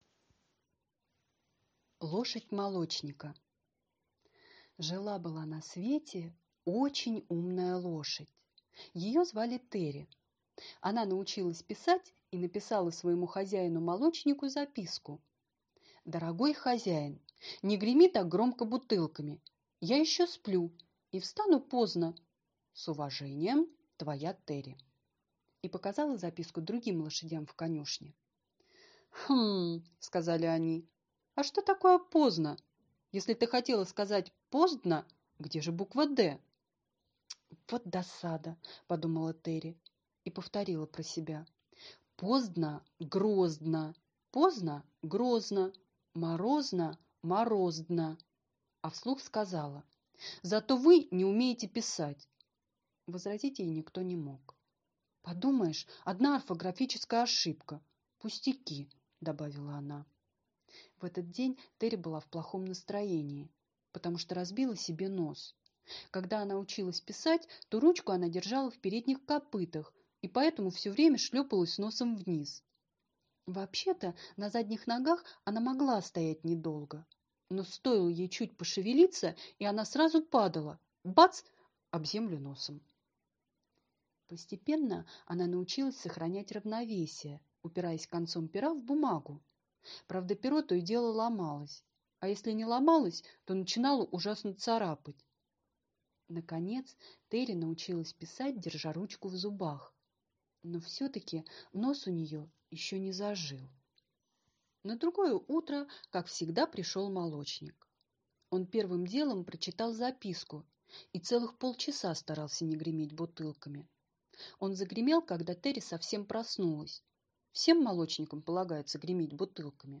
Аудиосказка «Лошадь молочника»